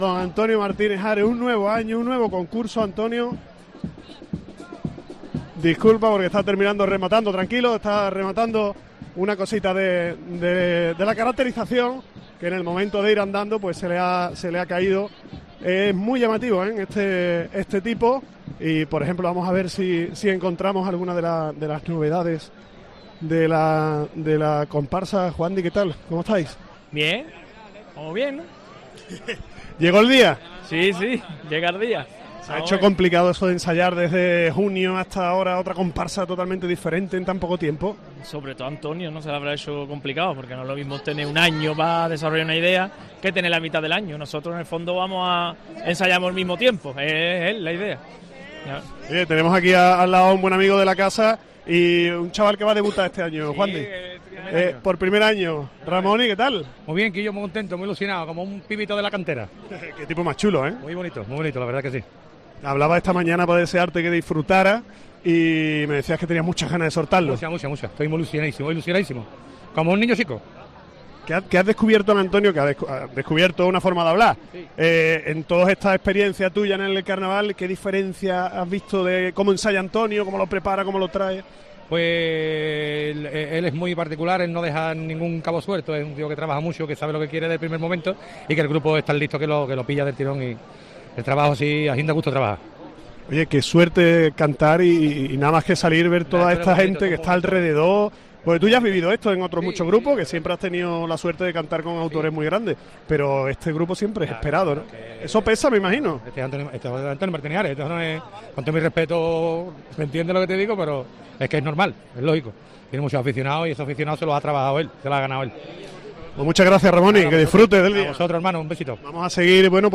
La ciudad invisible, la comparsa de Martínez Ares en COPE